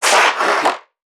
NPC_Creatures_Vocalisations_Infected [105].wav